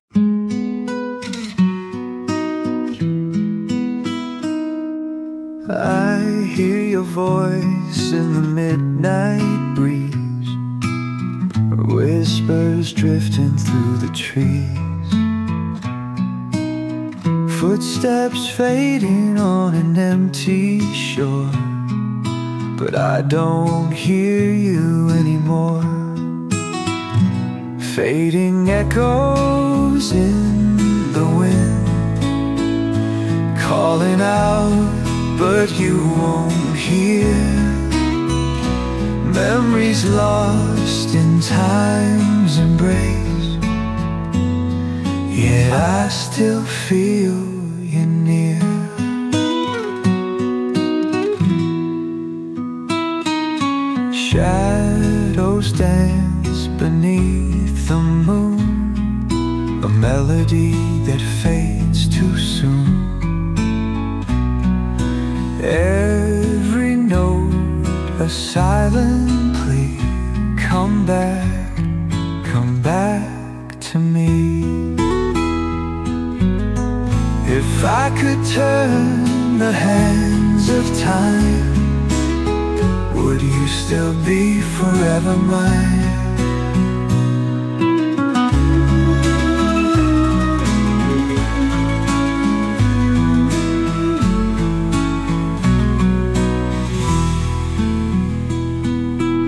Two versions are given, with the same lyrics and style but differing in vocal tone; one can be deeper and more emotional while the other might have a soft whispery feeling.